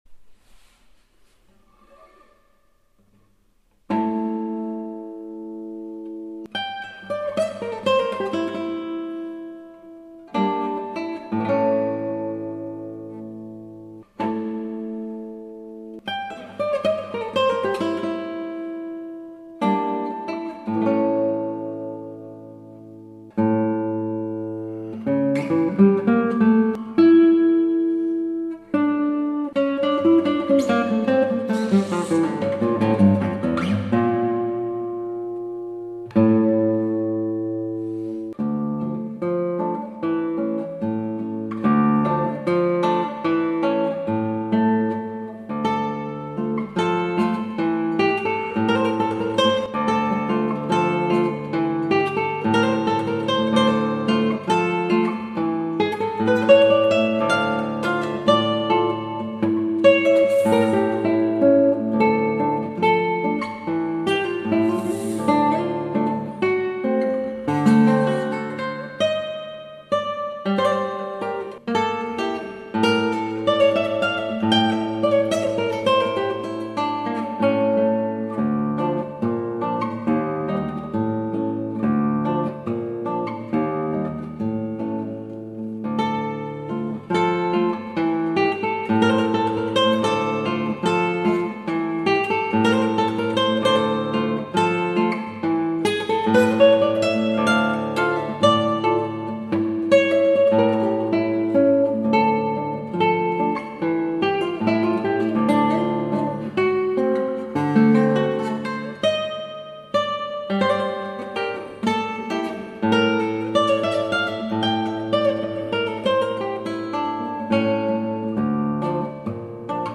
アラビア風奇想曲、バルベロで